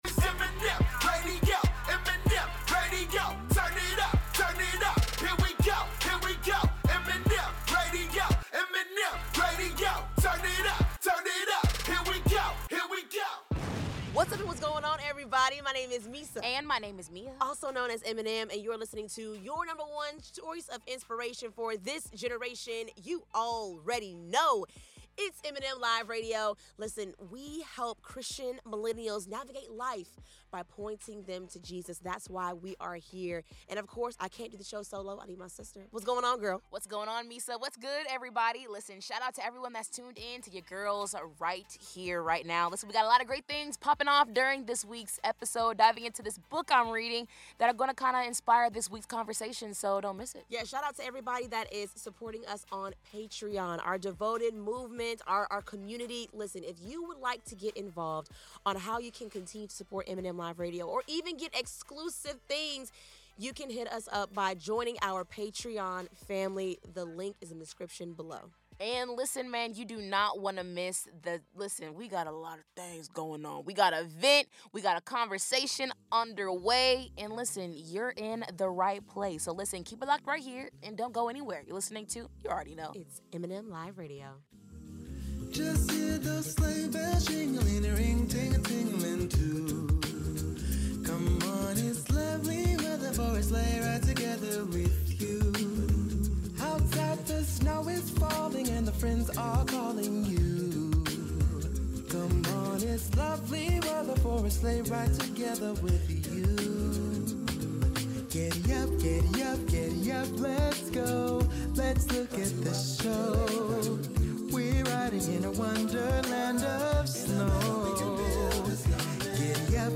They explore why God leads us into hidden seasons, how oversharing can drown out His voice, and the beauty of choosing faithfulness over visibility. This honest conversation digs into hustle culture, toxic transparency, and learning to hear God in the quiet.